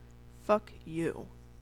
Uttal
IPA: /ˈalˌmɛn/